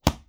Close Combat Attack Sound 11.wav